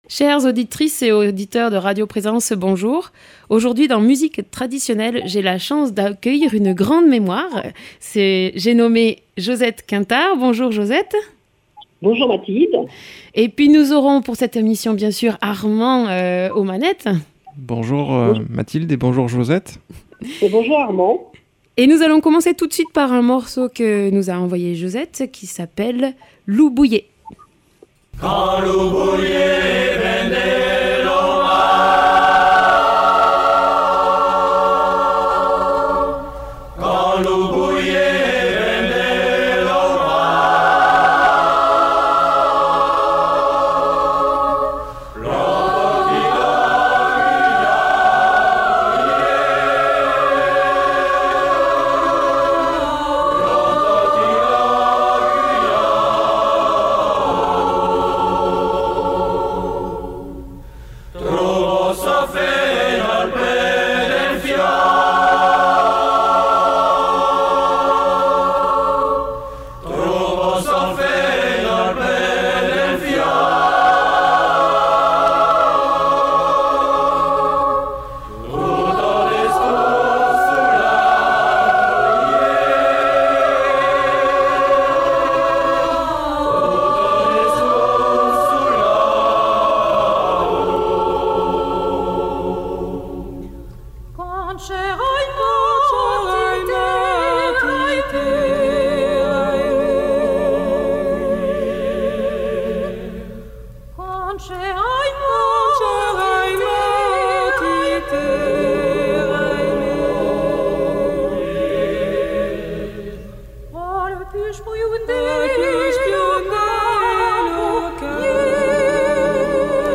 au studio de Radio Présence Figeac
Musique Traditionnelle